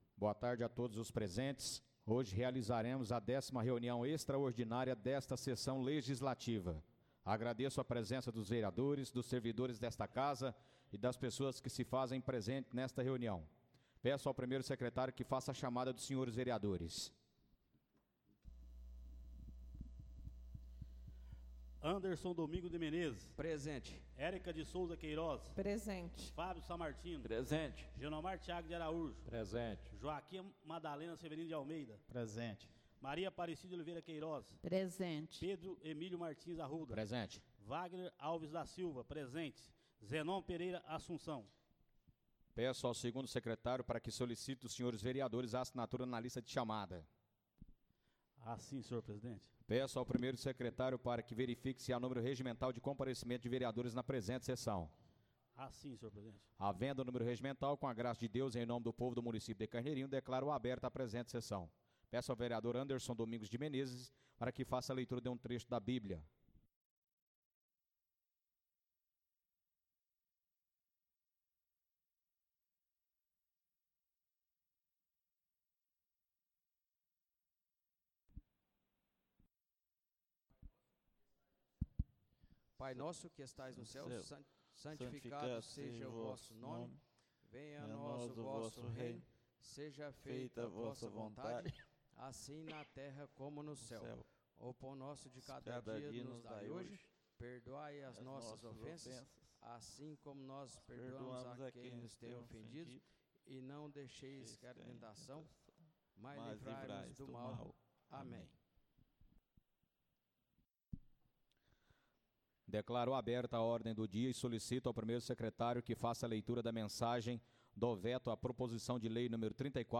Áudio da 10.ª reunião extraordinária de 2024, realizada no dia 30 de Dezembro de 2024, na sala de sessões da Câmara Municipal de Carneirinho, Estado de Minas Gerais.